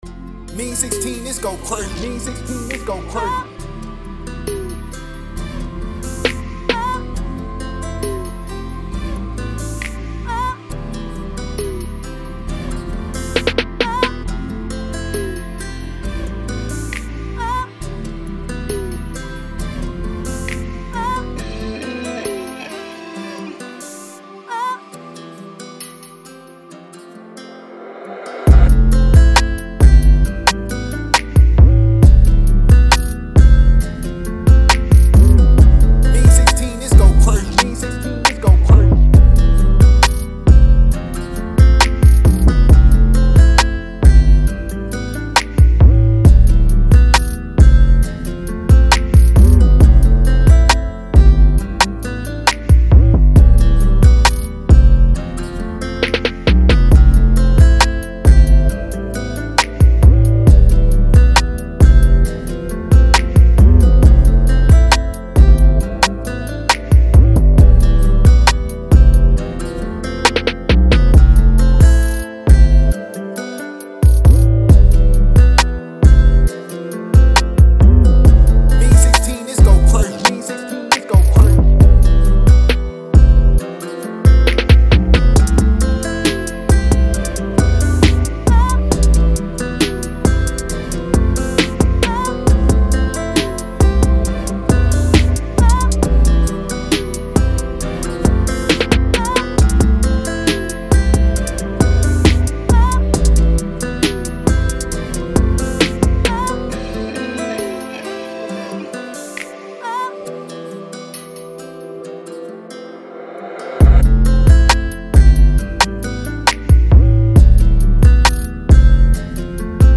D#-Min 141-BPM